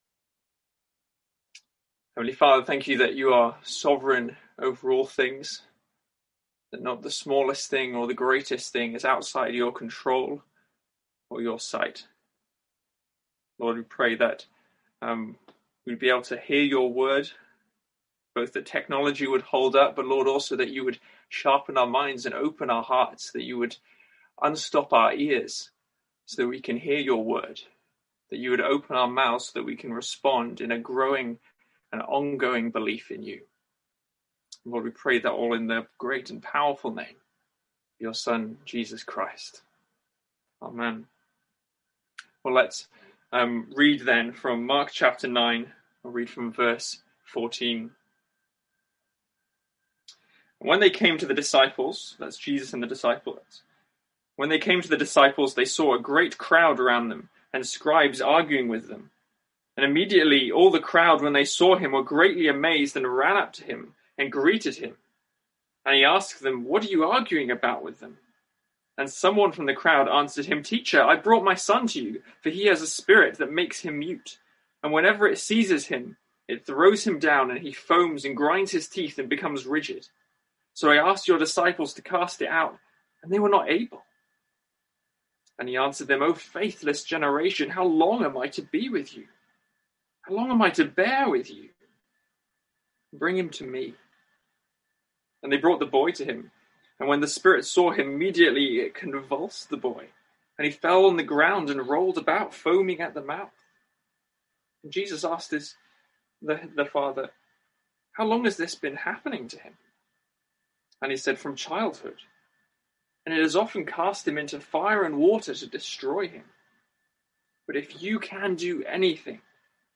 Sermons | St Andrews Free Church
From our evening service.